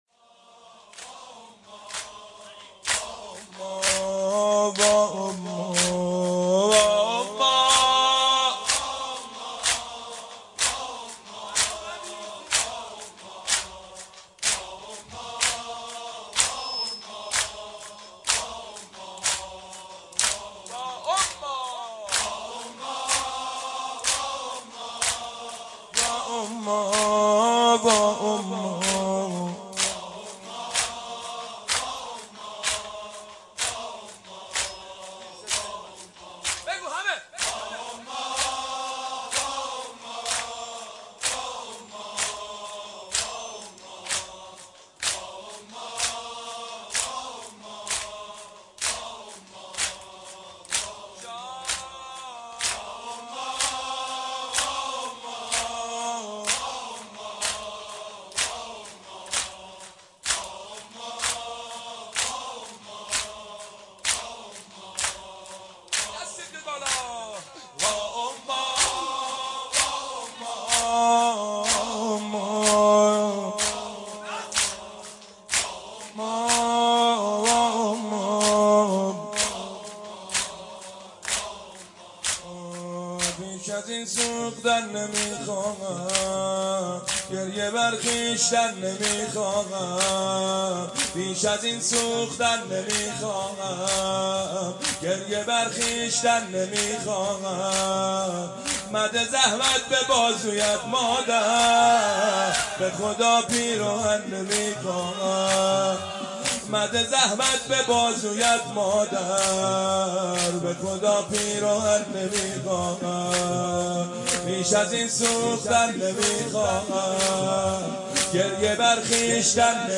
(زمینه)